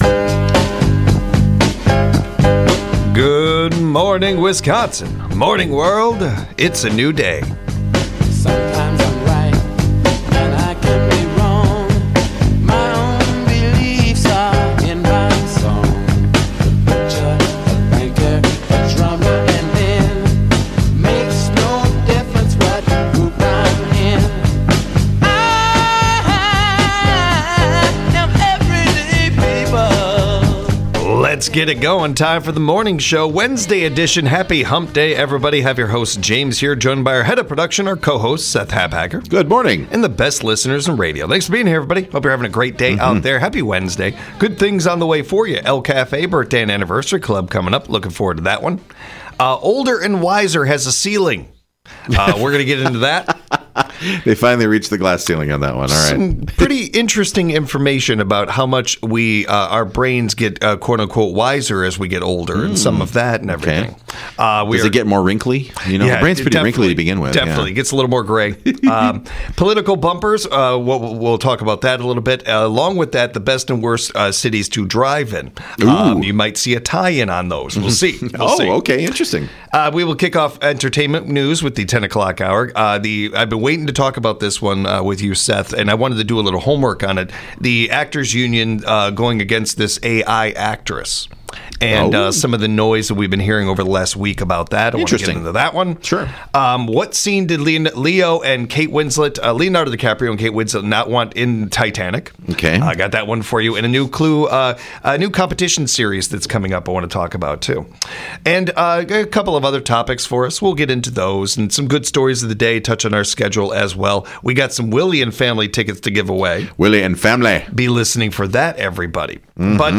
The boys discuss how early is too early for Christmas decor.